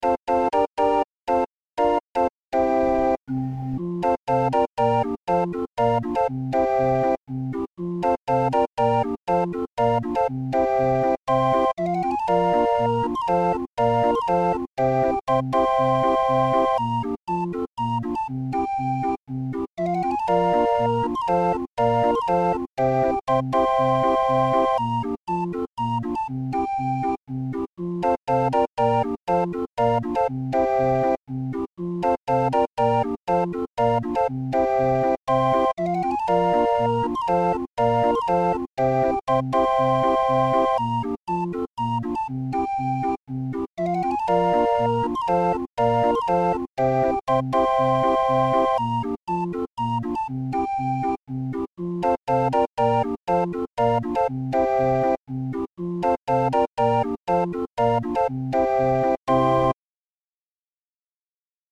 Muziekrol voor Raffin 31-er